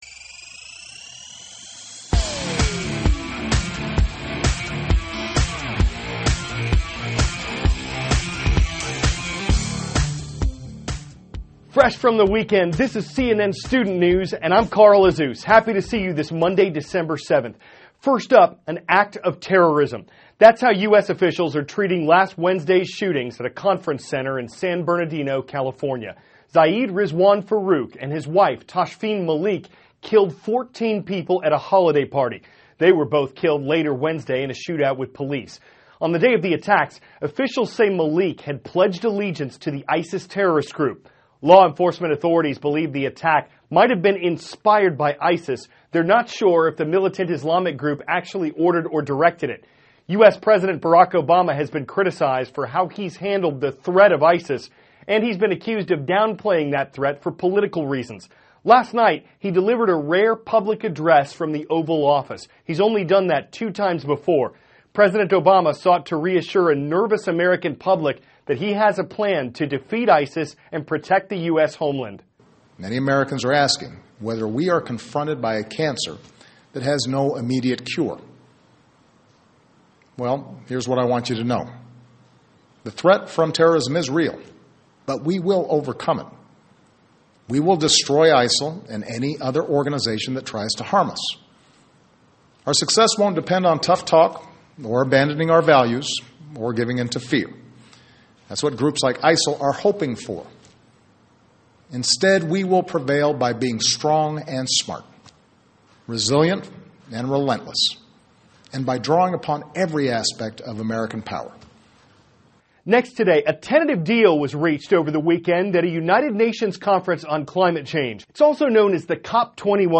(CNN Student News) -- December 7, 2015 Obama Addresses the U.S. About Terrorism; Preparations Made for Potential Solar Storms, The Complicated Tasks of Our Blood THIS IS A RUSH TRANSCRIPT.